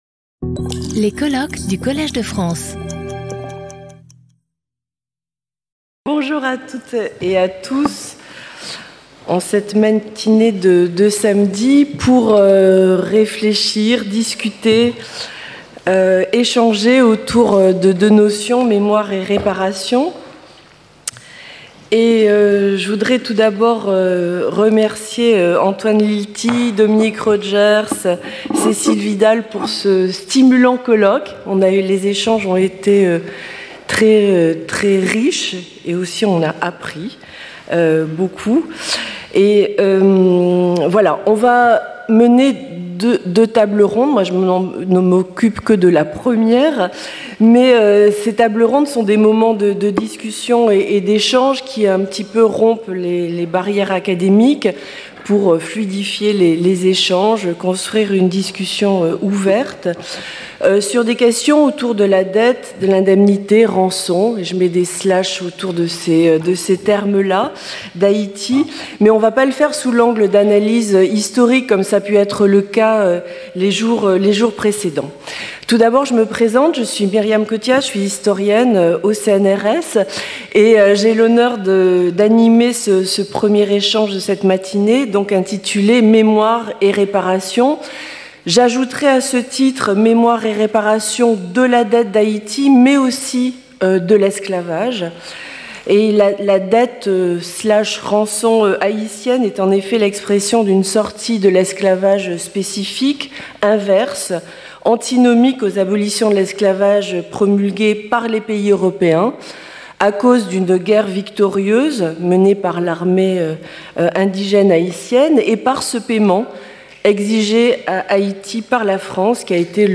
Round table : Remembrance and reparations | Collège de France